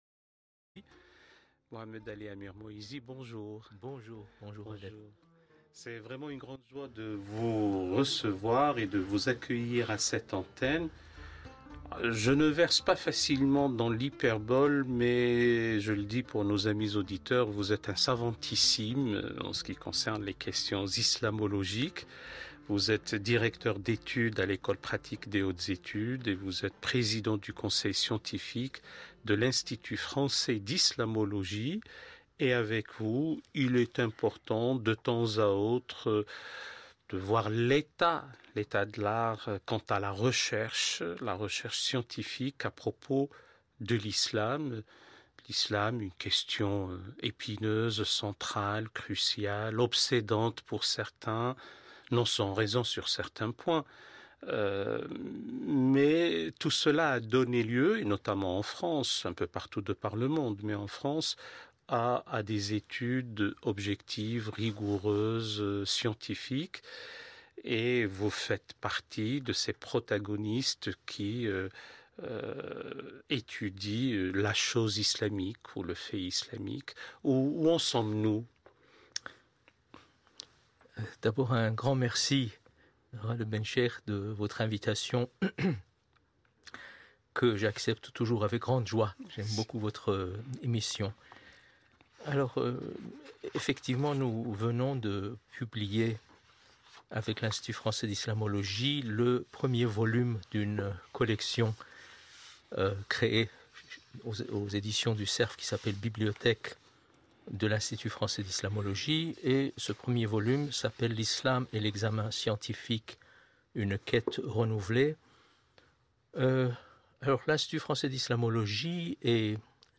Entretien dans l'émission Question d'Islam sur France Culture : Comment fonder l'étude objective, rigoureuse et distanciée de l'islam face aux dérives qu'engendre sa politisation ?